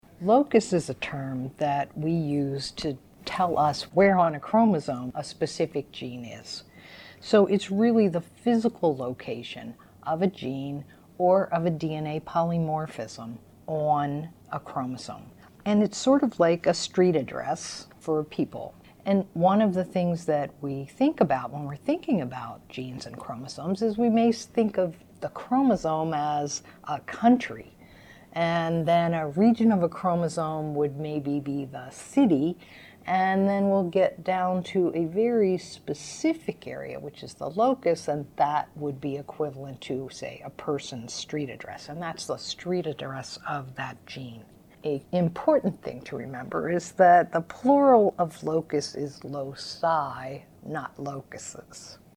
10.1 Talking Glossary: Locus (1 min)